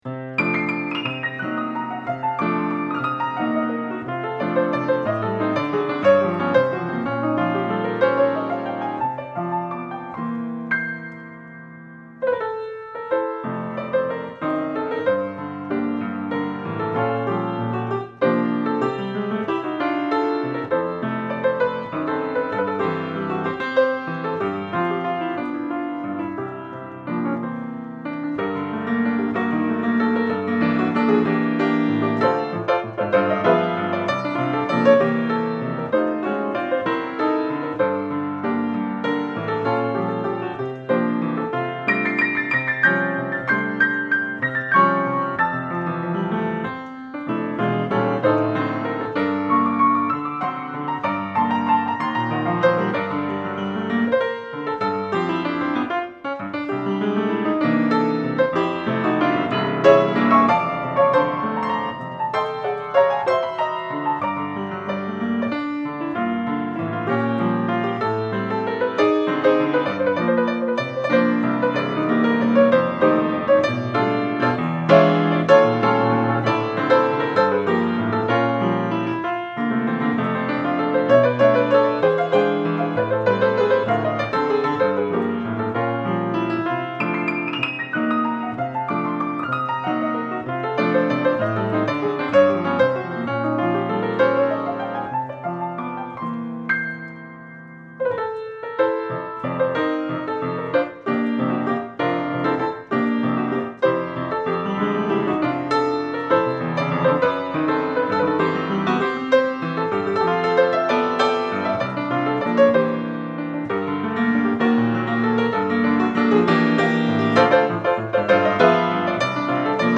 Easy Listening
Cocktail Music
Piano Jazz , Solo Piano